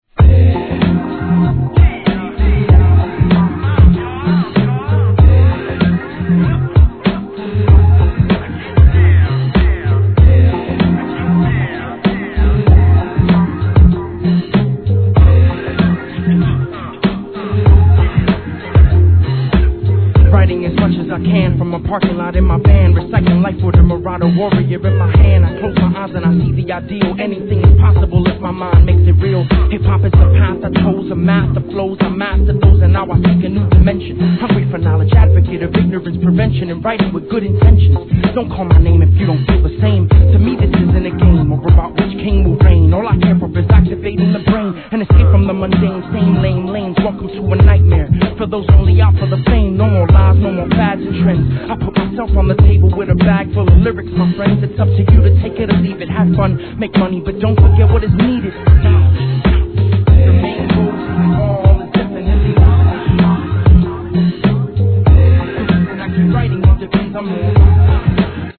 HIP HOP VINYL